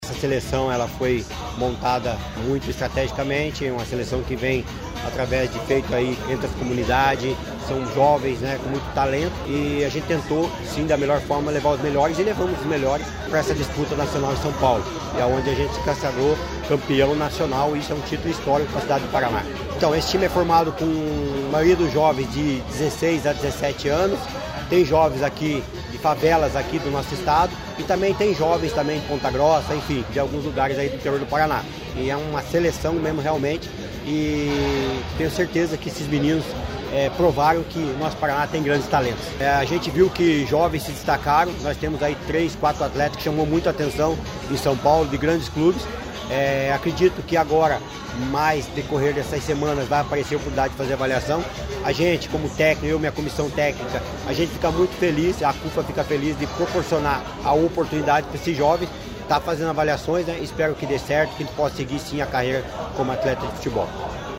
Sonora do técnico da equipe